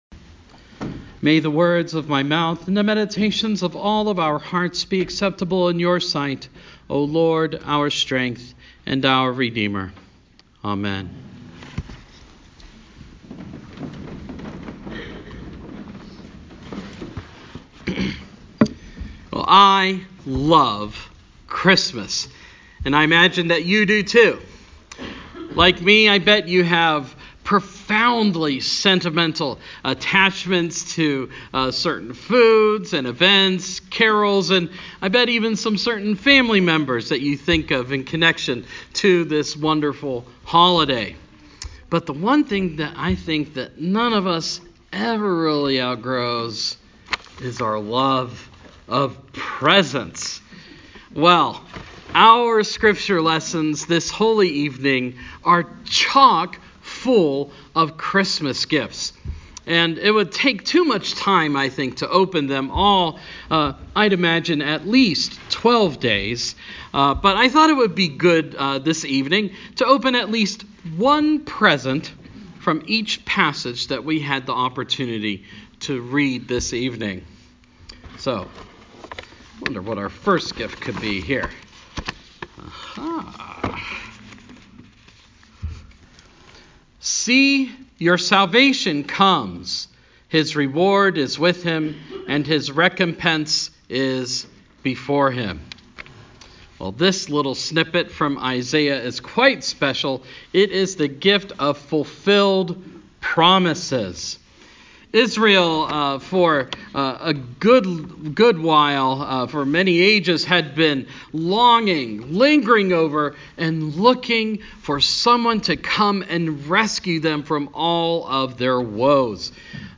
Christmas Eve – 2018